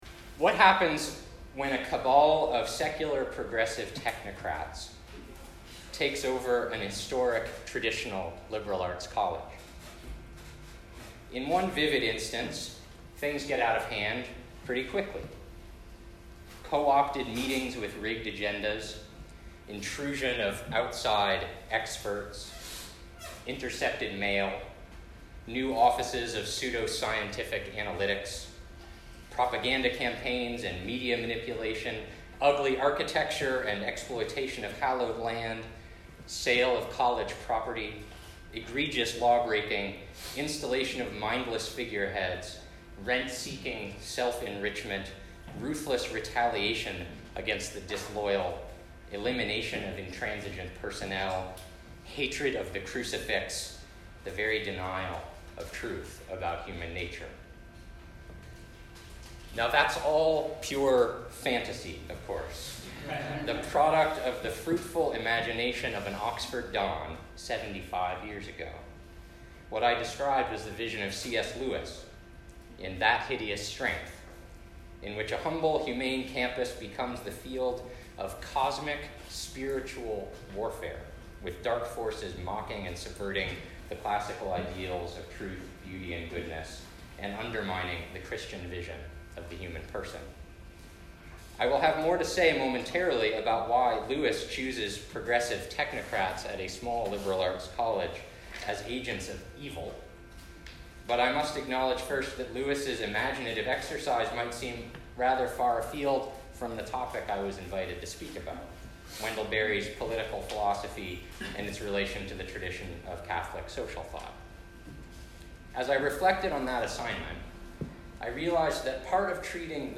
This lecture was given at the University of Texas at Austin on 24 October 2019.